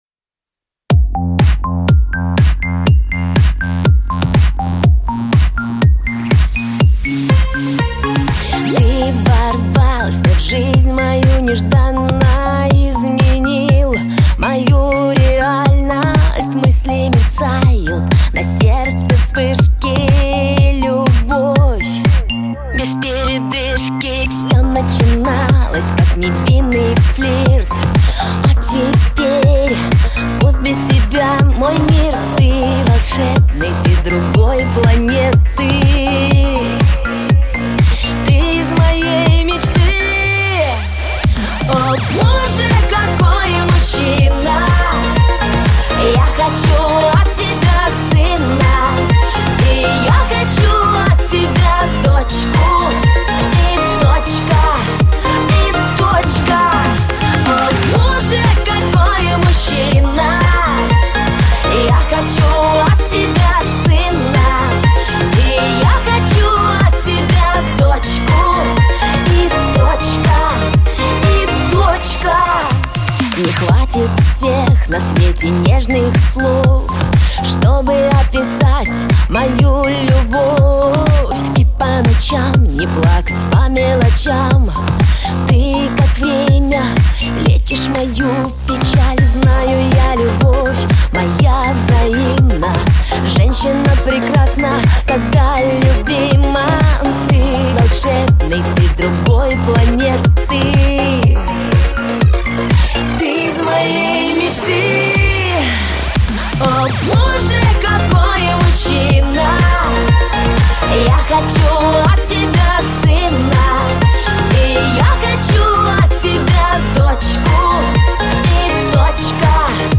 Раздел: Музыка » Pop